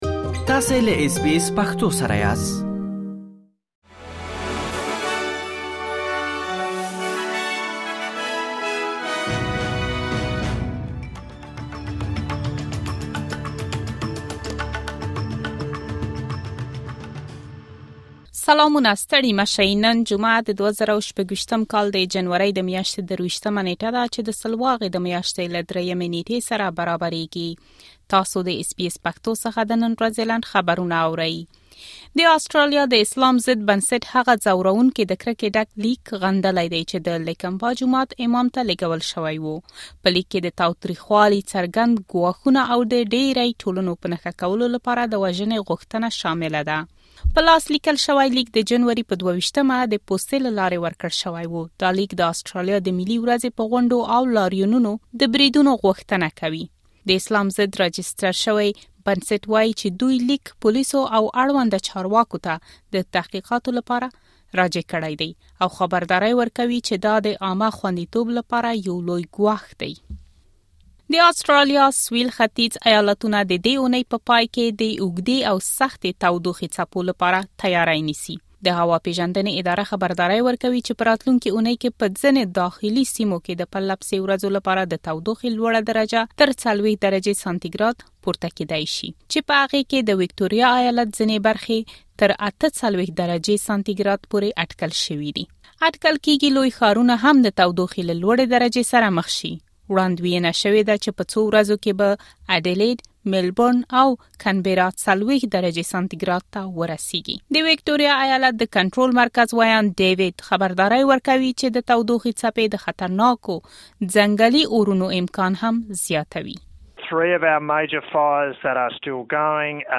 د اس بي اس پښتو د نن ورځې لنډ خبرونه |۲۳ جنوري ۲۰۲۶